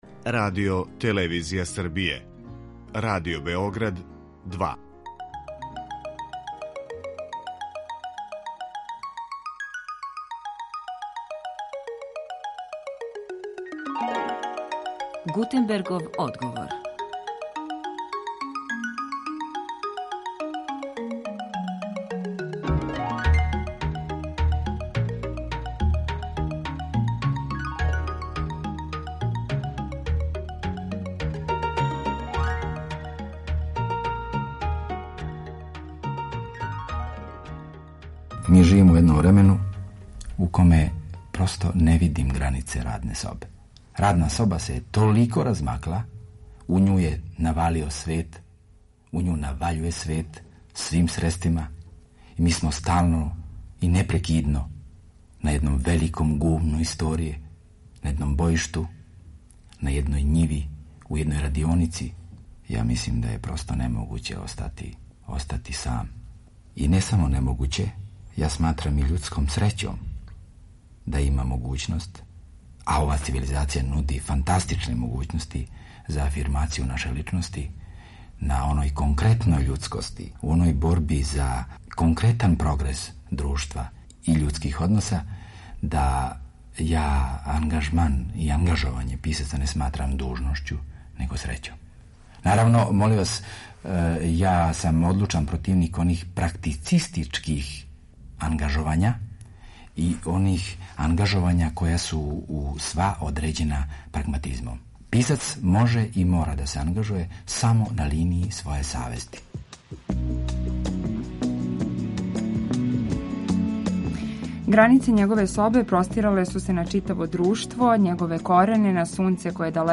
Посебна посластица биће Ћосићеви искази и мисли о стварању које смо пронашли у Тонском архиву Радио Београда.